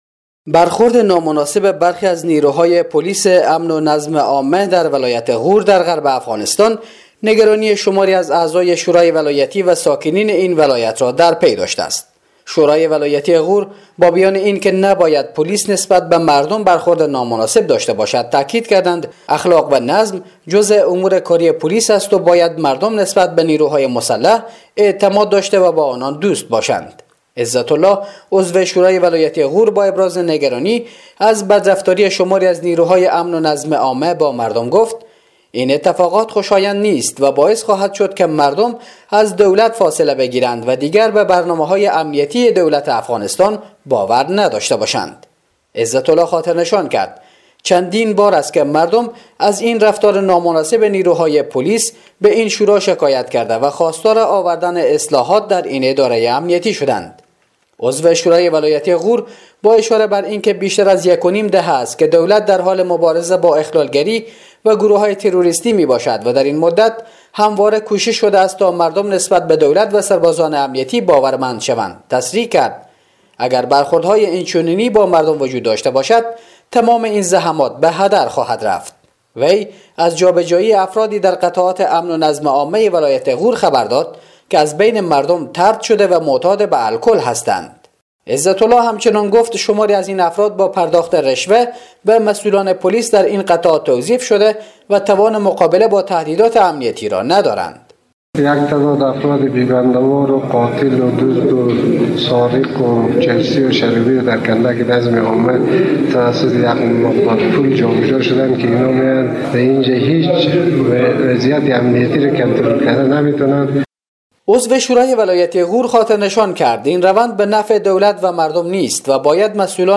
به گزارش خبرنگار رادیو دری، شورای ولایتی غور با بیان اینکه نباید پلیس نسبت به مردم برخورد نامناسب داشته باشد، تاکید کردند: اخلاق و نظم جزء امور کاری پلیس است و باید مردم نسبت به نیروهای مسلح اعتماد داشته و با آنان دوست باشند.